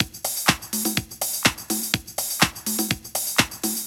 • tech house fill with ride.wav
tech_house_fill_with_ride_Xj3.wav